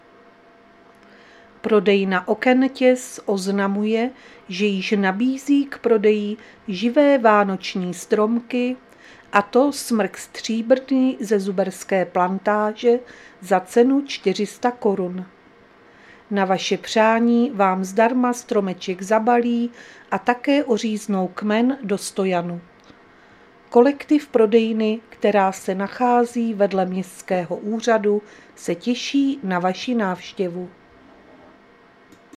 Záznam hlášení místního rozhlasu 3.12.2025